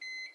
Negative Beep.wav